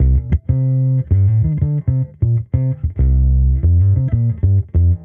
Index of /musicradar/sampled-funk-soul-samples/95bpm/Bass
SSF_PBassProc1_95C.wav